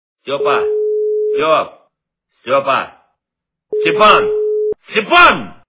» Звуки » Именные звонки » Именной звонок для Степана - Степа, Степ, Степа, Степан, Степан